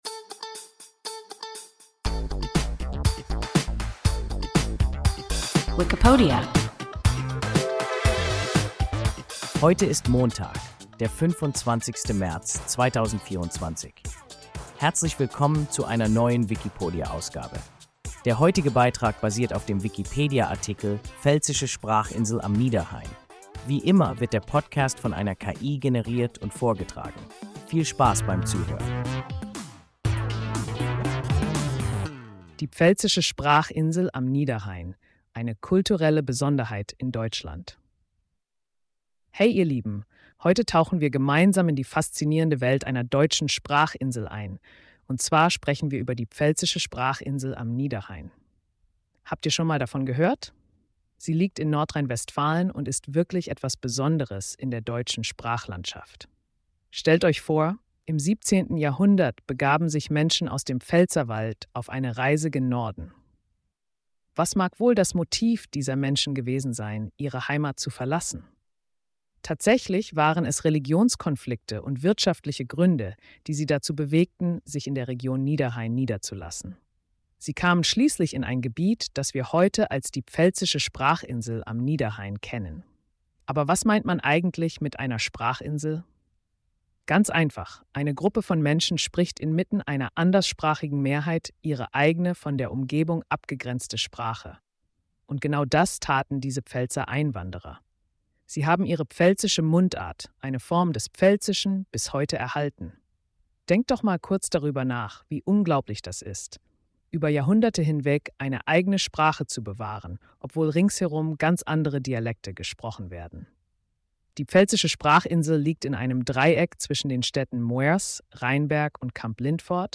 Pfälzische Sprachinsel am Niederrhein – WIKIPODIA – ein KI Podcast